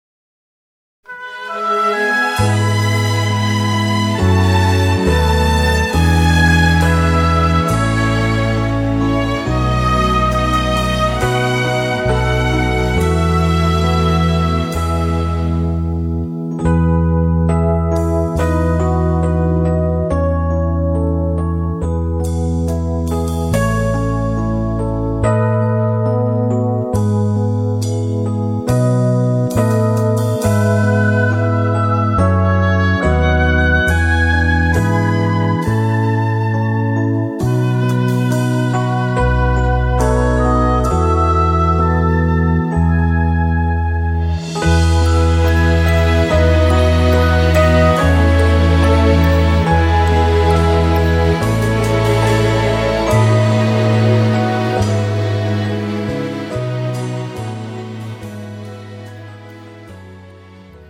MR 고음질 반주